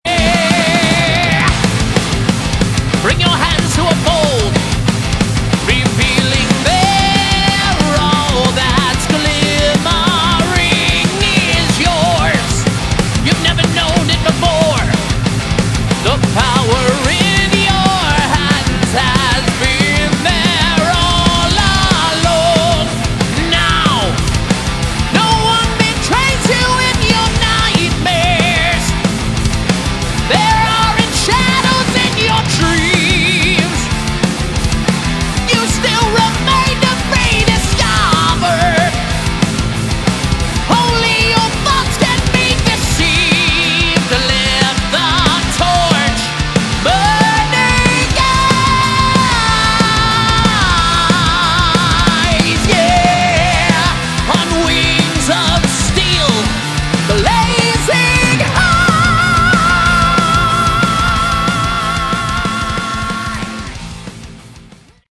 Category: Melodic Metal
lead & backing vocals
Guitars
bass
drums